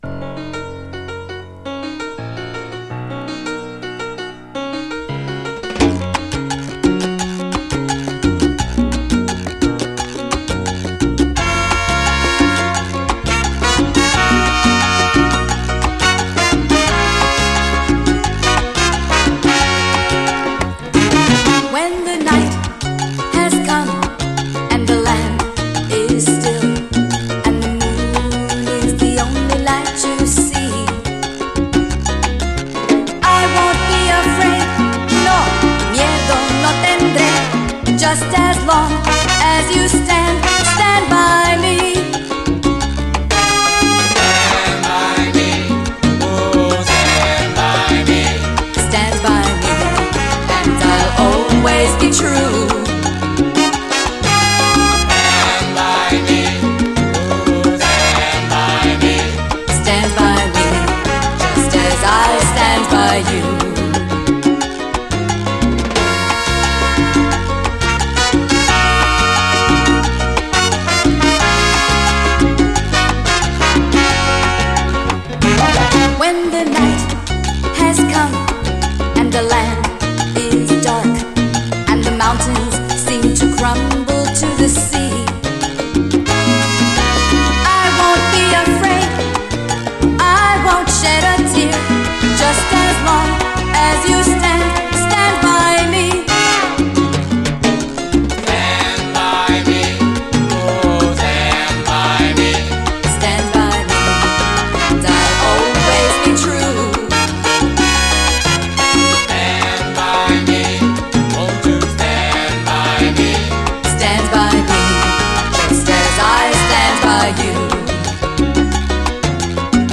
SALSA, LATIN